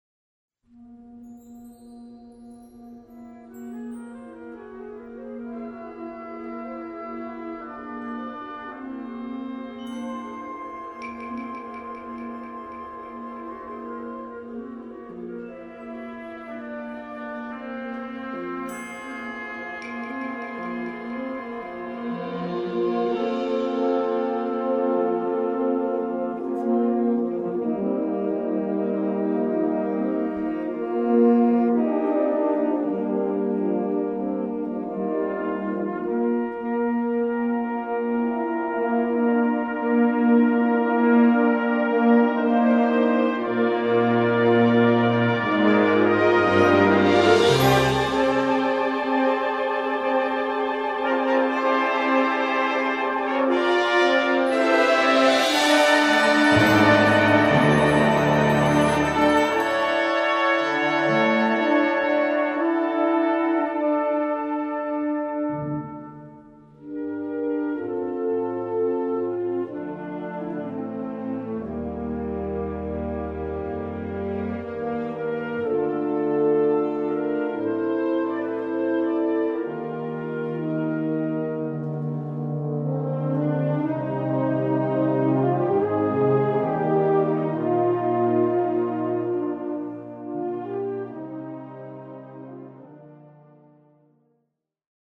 Répertoire pour Orchestre